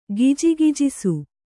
♪ gijigijisu